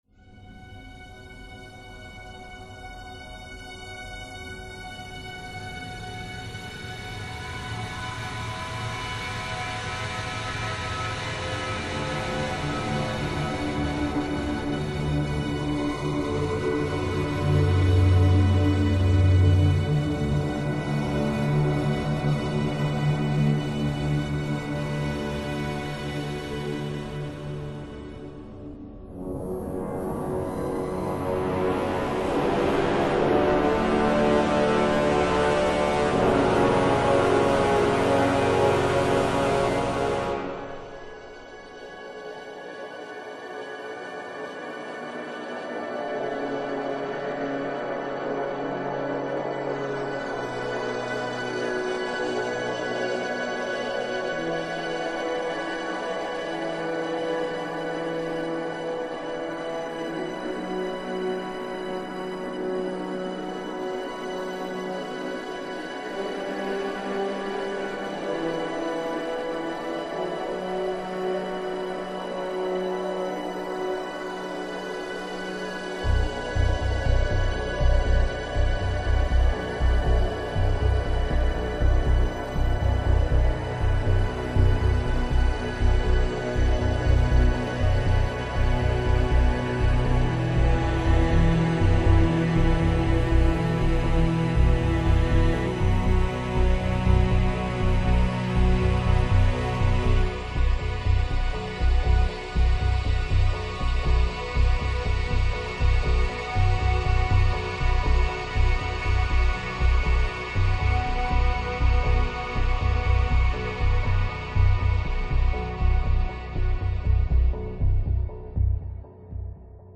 They felt like space - big and indifferent.